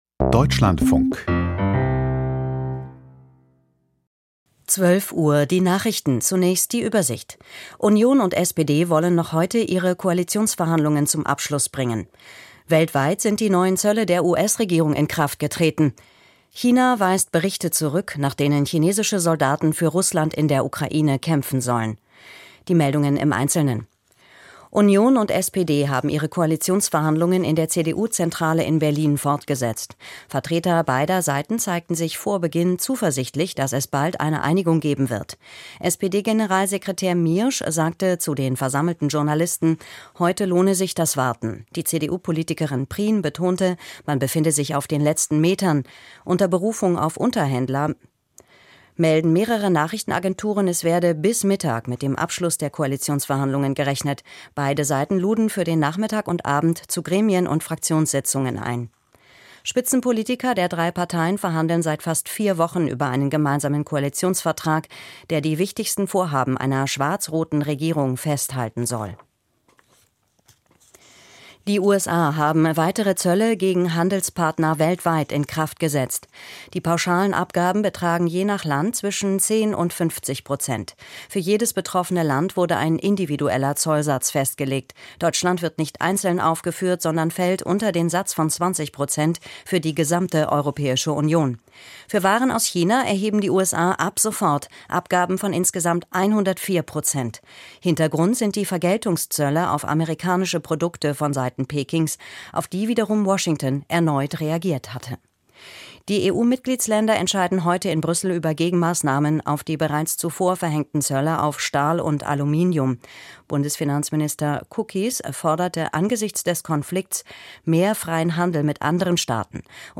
Die Deutschlandfunk-Nachrichten vom 09.04.2025, 12:00 Uhr